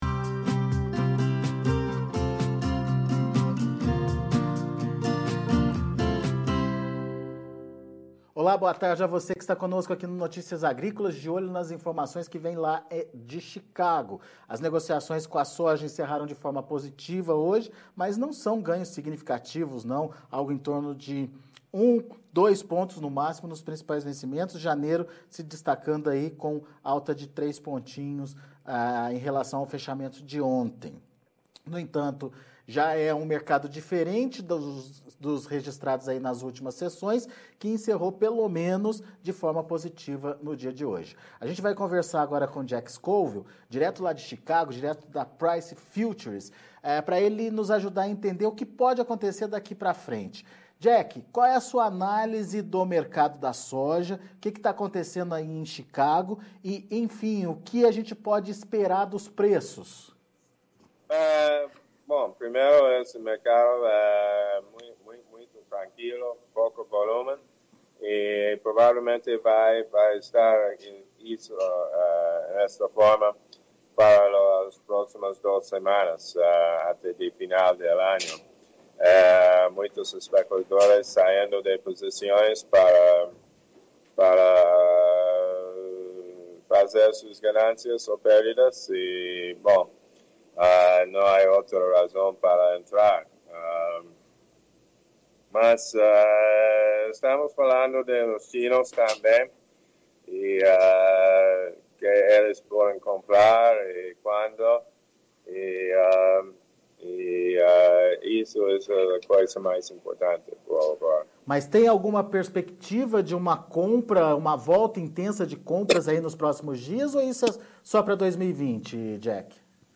Fechamento de Mercado da Soja – Entrevista